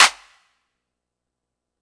Lean Wit It Clap.wav